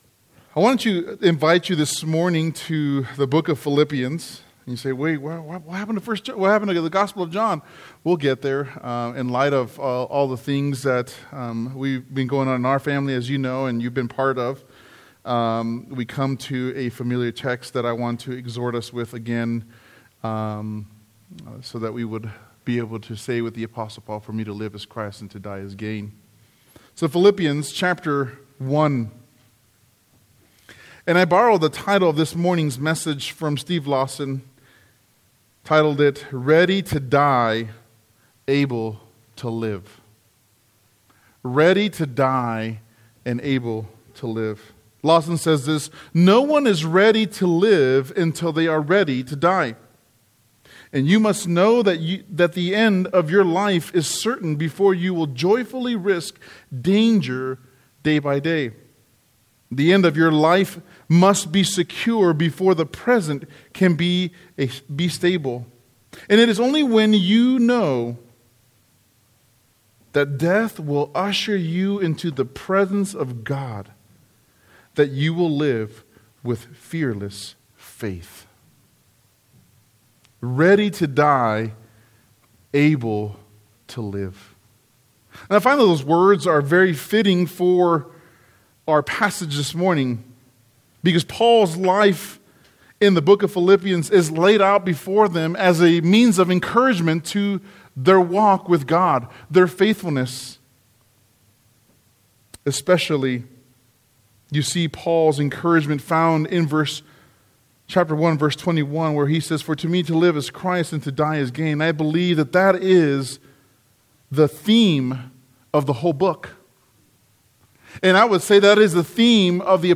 The message on Sunday